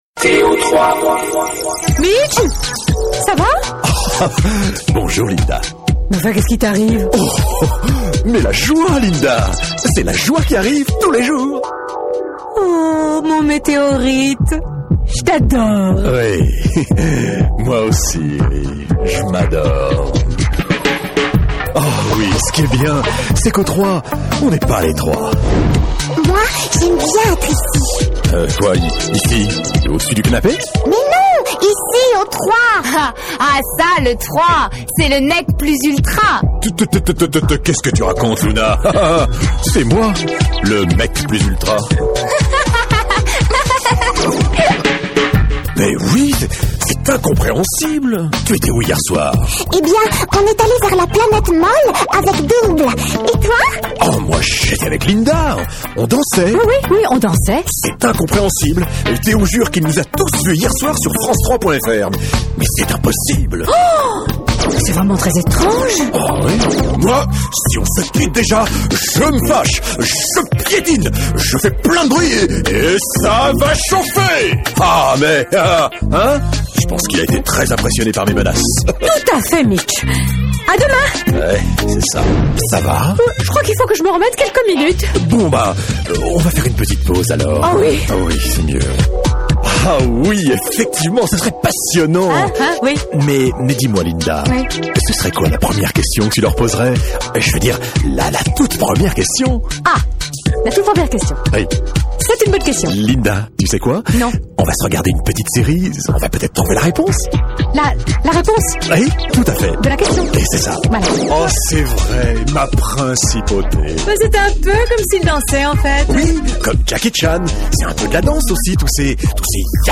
Habillage France 3 jeunesse